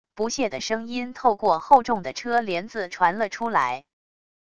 不屑的声音透过厚重的车帘子传了出来wav音频生成系统WAV Audio Player